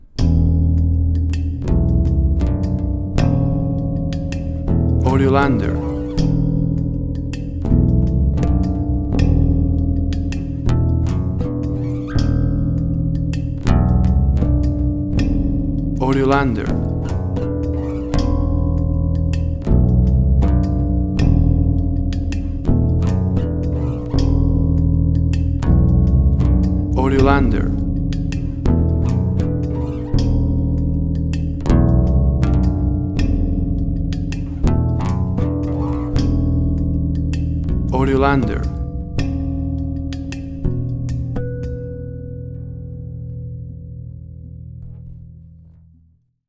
WAV Sample Rate: 24-Bit stereo, 48.0 kHz
Tempo (BPM): 82